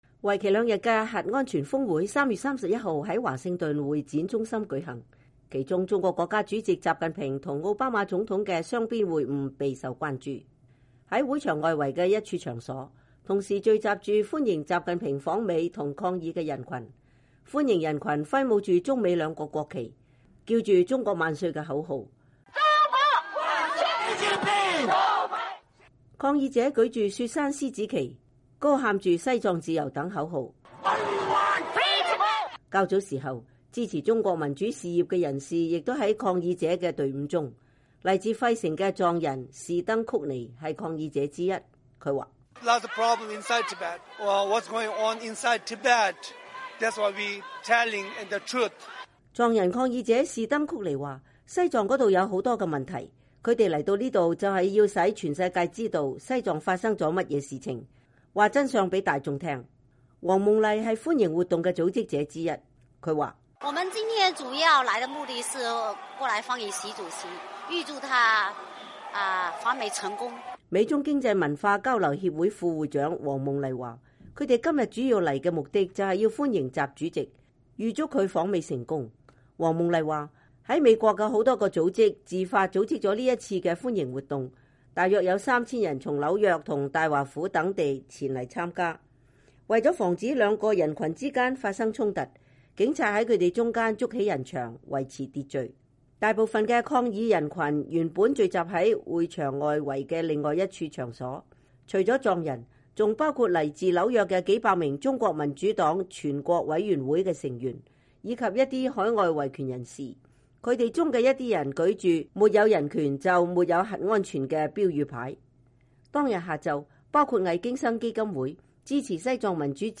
歡迎人群揮舞著中美兩國國旗，喊著‘中國萬歲’的口號。抗議者舉著‘雪山獅子旗’，喊著‘西藏自由’等口號。